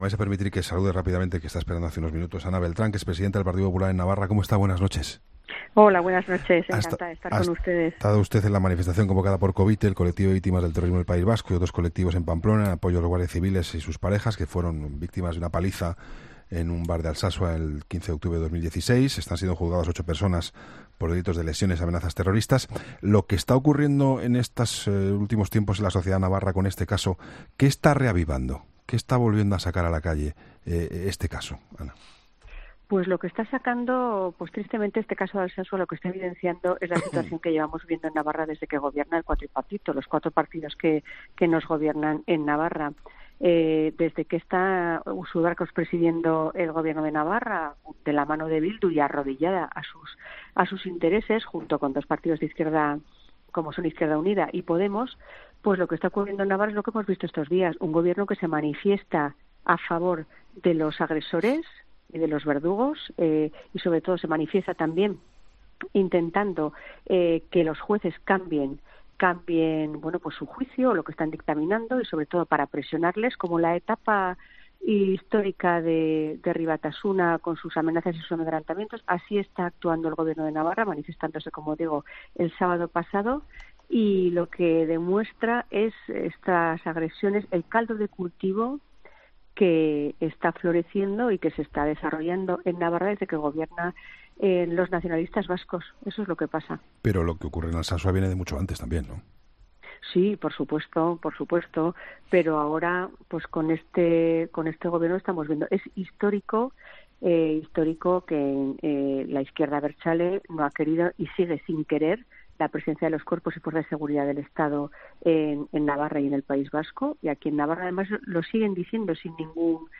Entrevistas en La Linterna
Juan Pablo Colmenarejo entrevista en 'La Linterna' a Ana Beltrán, presidenta del Partido Popular de Navarra, a raíz del juicio de los ocho acusados de agredir a los dos guardias civiles y sus novias en Alsasua, Navarra, en octubre de 2016